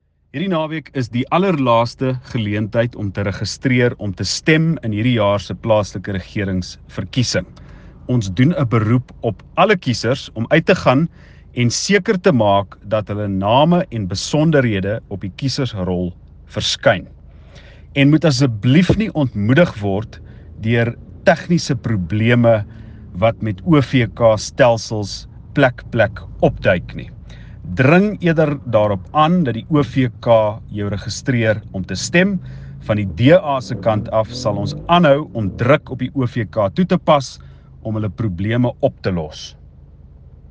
soundbite by Cilliers Brink